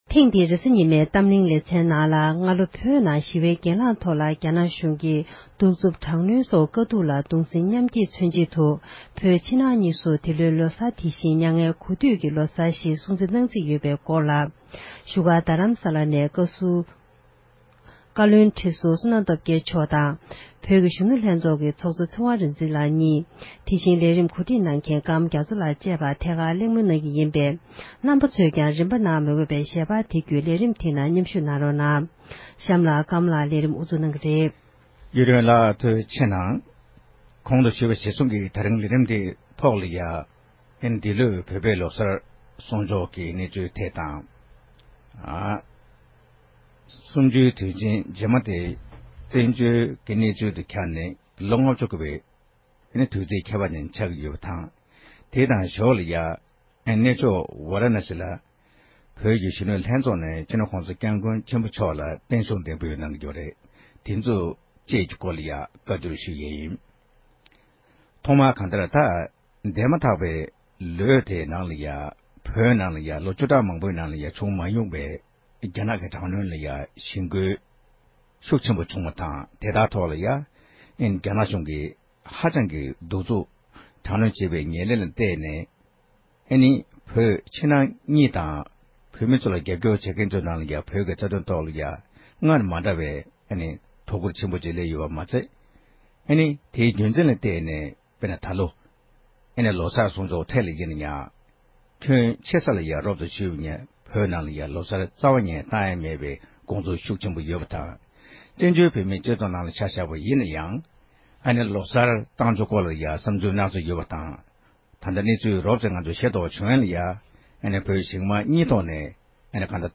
གཏམ་གླེང་གི་ལེ་ཚན